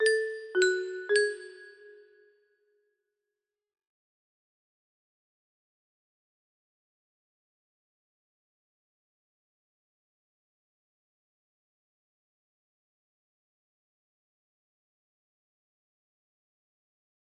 test music box melody